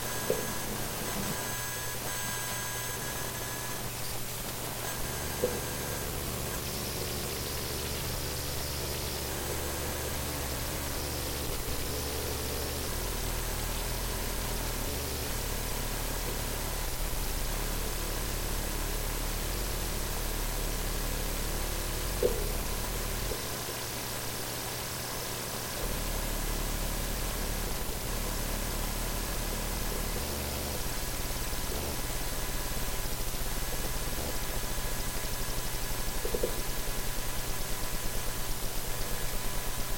电脑之声 " 电脑之哼2
描述：单声道录制的电脑（风扇）噪音，就在侧边风扇旁边。可循环播放。
标签： 加入影片箱 嗡嗡 噪声 风扇 计算机 嗡嗡声